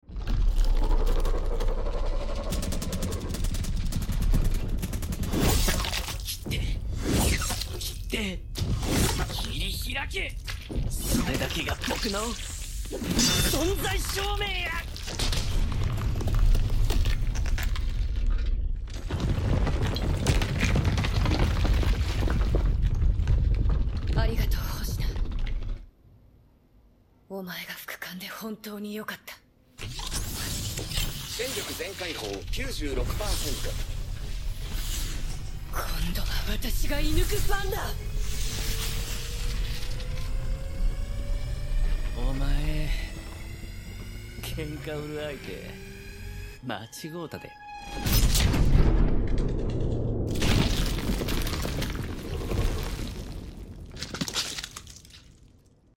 The binaural version of the Atmos mix works best on headphones.
The kaiju roars, the energy swords/ machine guns being the main ones designed mostly from scratch.
My favorite part to automate was the electricity during the weapon charge up– I ended up making a ton of breakpoints so the objects jumped around the listener’s head.